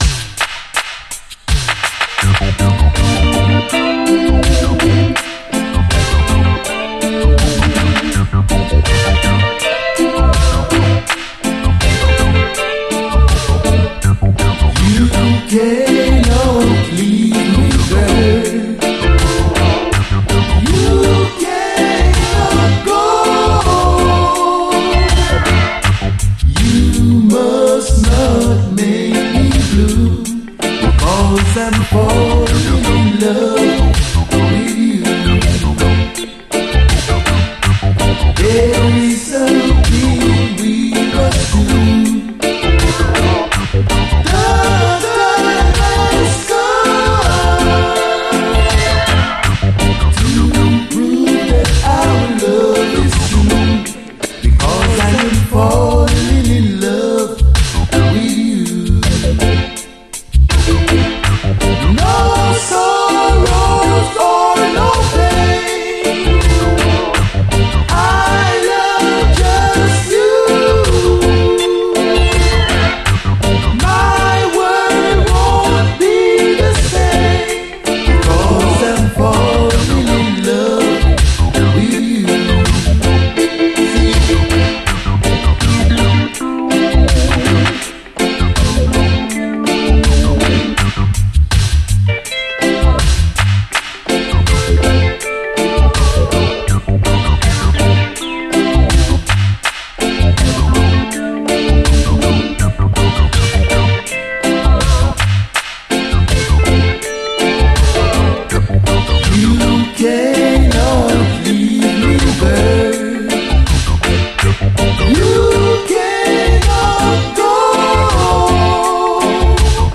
REGGAE
曲の素晴らしさは言わずもがな、ピュンピュングニュグニュしたオケもよいです。ダブも収録。